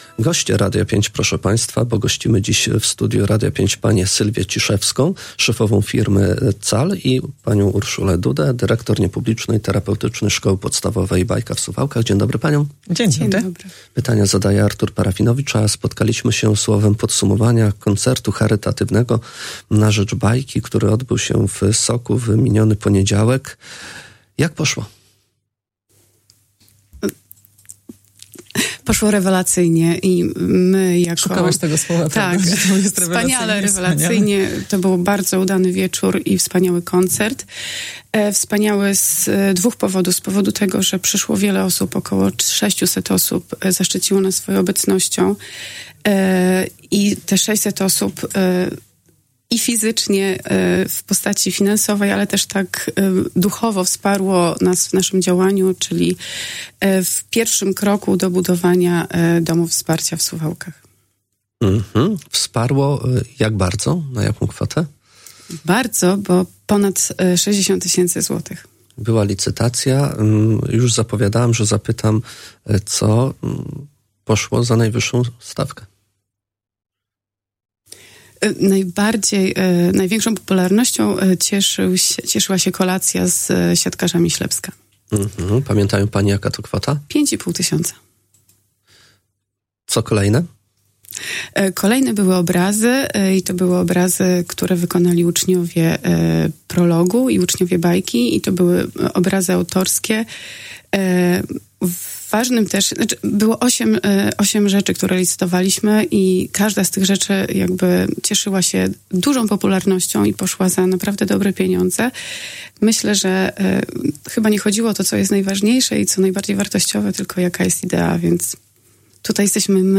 Tegoroczny koncert i licytację podsumowały dziś w Radiu 5